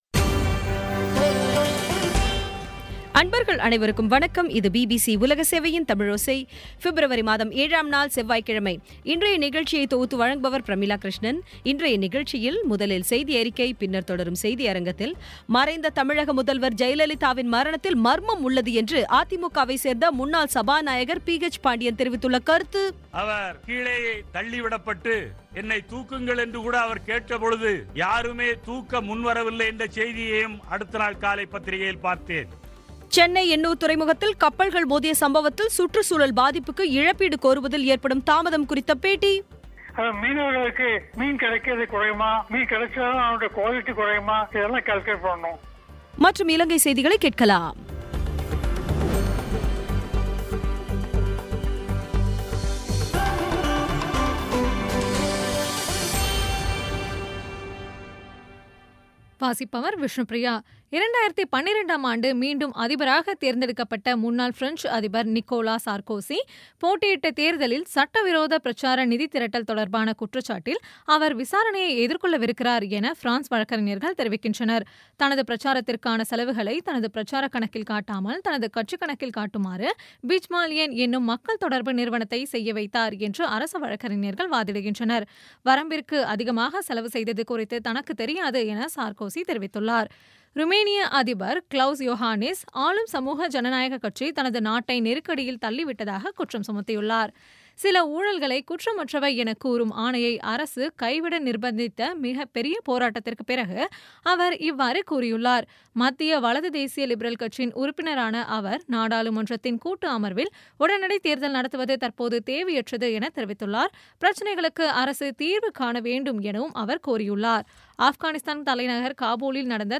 இன்றைய தமிழோசையில், முதலில் செய்தியறிக்கை, பின்னர் தொடரும் செய்தியரங்கத்தில், மறைந்த தமிழக முதல்வர் ஜெயலலிதாவின் மரணத்தில் மர்மம் உள்ளது என அ.தி.மு.கவைச் சேர்ந்த முன்னாள் சபாநாயகர் பி.ஹெச். பாண்டியனின் தெரிவித்துள்ள கருத்து பற்றிய செய்தி சென்னை எண்ணூர் துறைமுகத்தில் கப்பல்கள் மோதிய சம்பவத்தில் சுற்றுசூழல் பாதிப்புக்கு இழப்பீடு கோருவதில் ஏற்படும் தாமதம் குறித்த பேட்டி மற்றும் இலங்கை செய்திகளை கேட்கலாம்